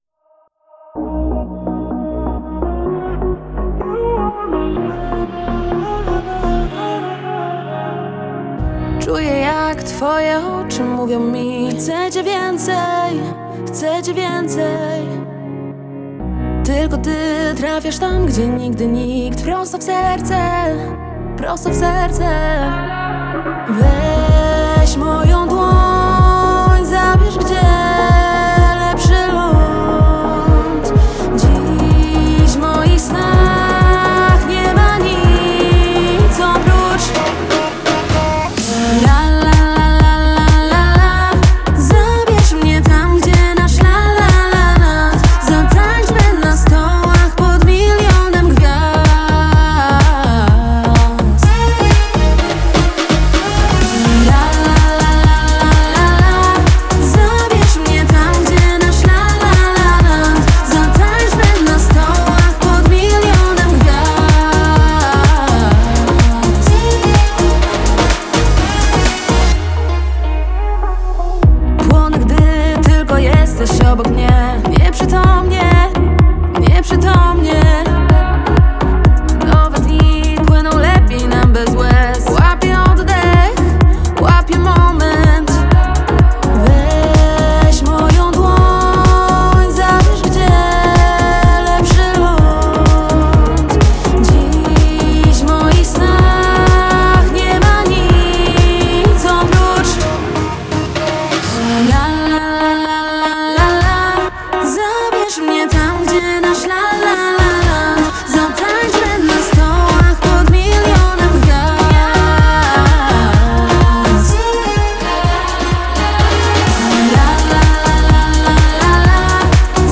radiowy energiczny pop
świetny wokal top produkcja